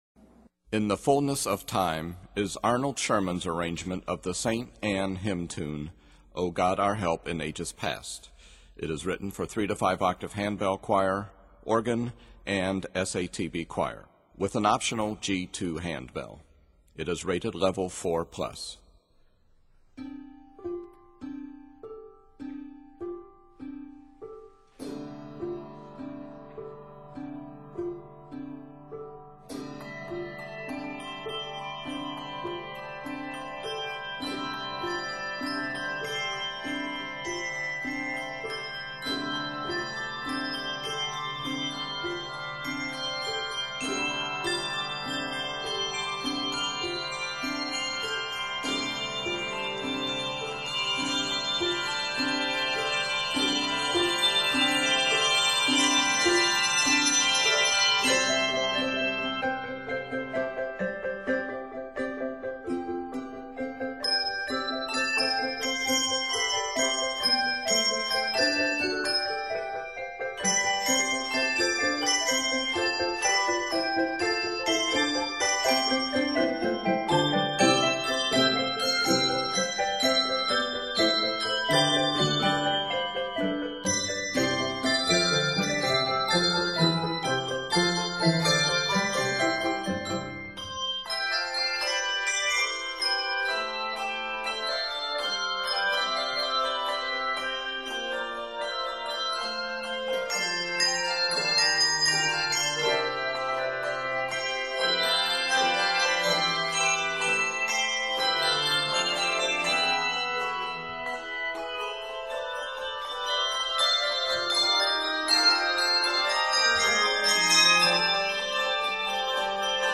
Set in C Major and f minor, this piece is 105 measures.